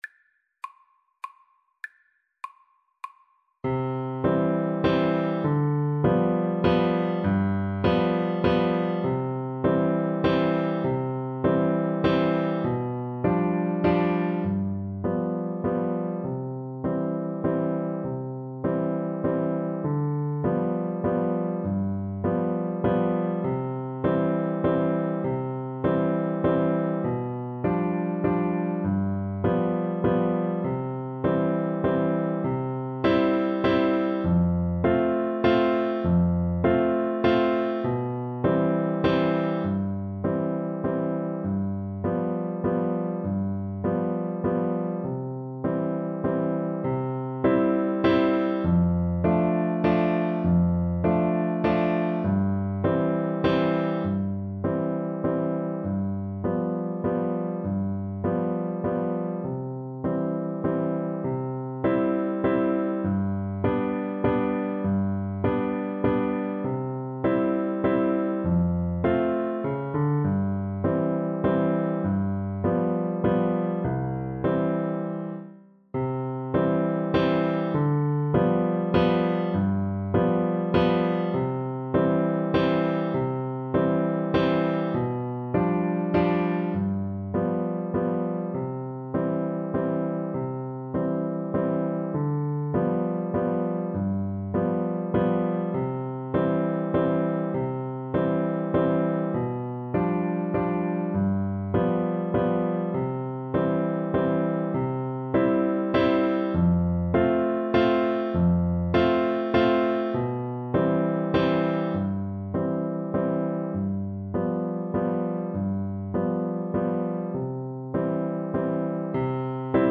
Play (or use space bar on your keyboard) Pause Music Playalong - Player 1 Accompaniment transpose reset tempo print settings full screen
C major (Sounding Pitch) (View more C major Music for Piano Duet )
Andantino (View more music marked Andantino)